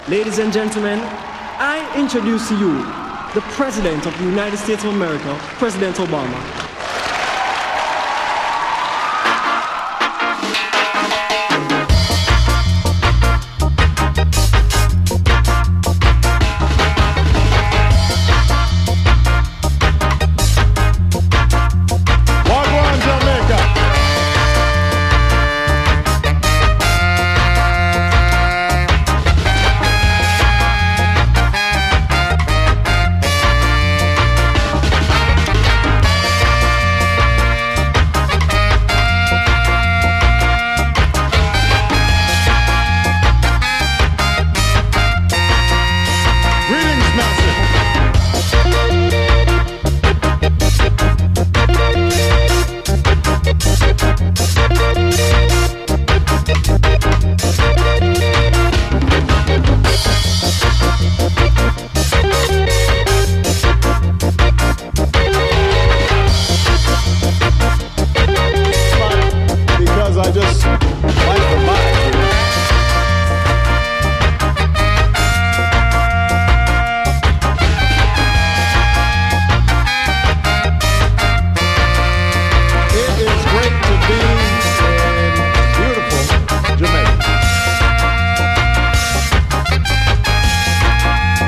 哀愁ロックステディ〜オルガンがはしゃぎたてる王道スキンヘッド・レゲエ・サウンド！
ドラムス
ベース
ギター
オルガン
ピアノ
トランペット
テナーサックス
トロンボーン